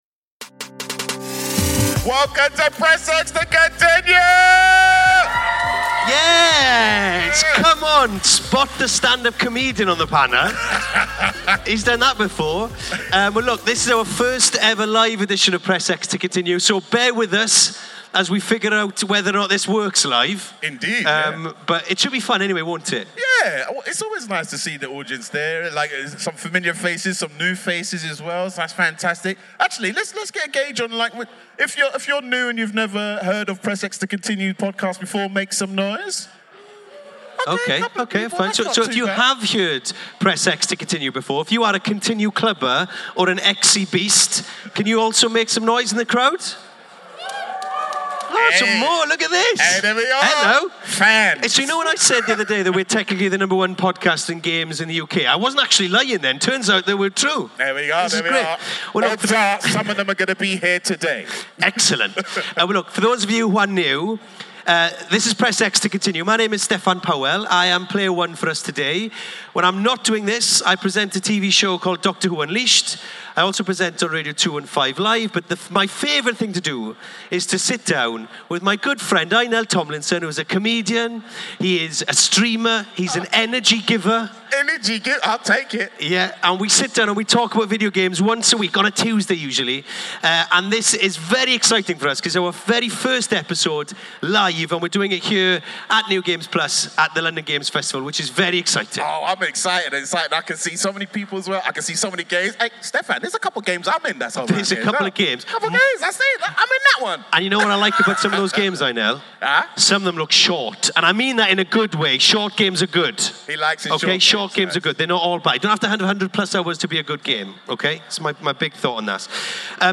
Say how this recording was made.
We were live at the New Game Plus during London Games Festival to talk nostalgia, Atomfall, and all sorts of stuff!